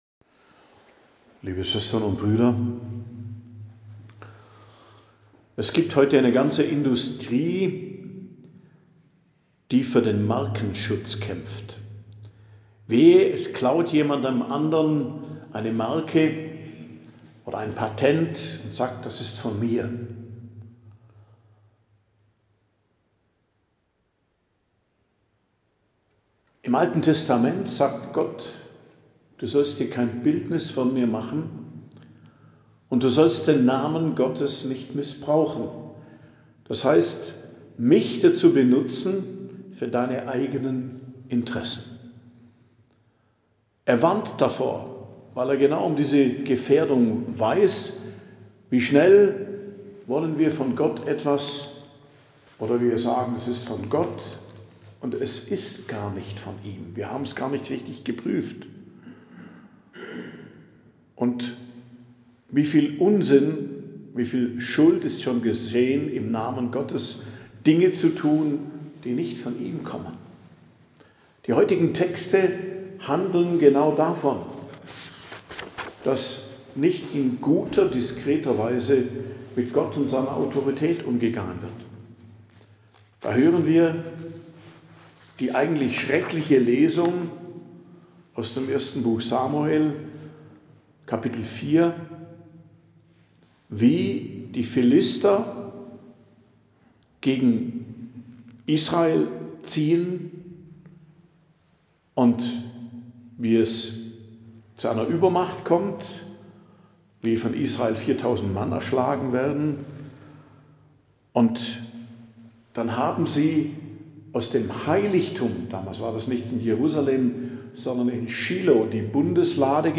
Predigt am Donnerstag der 1. Woche i.J., 15.01.2026